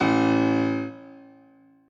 b_basspiano_v100l2o2a.ogg